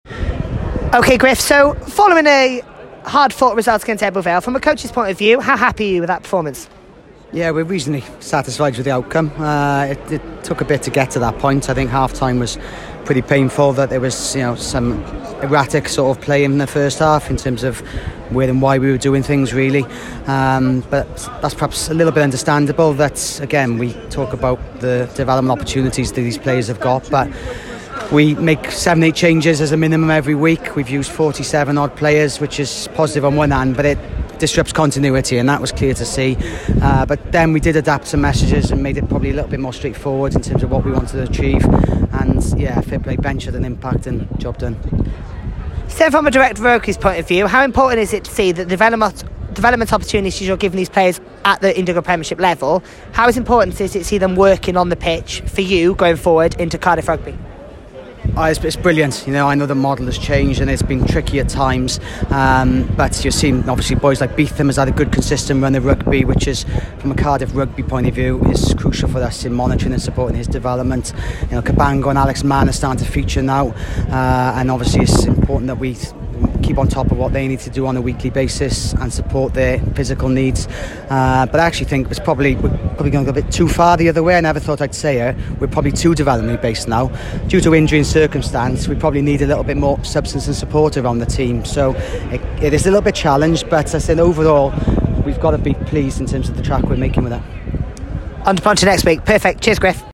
Post Match Interviews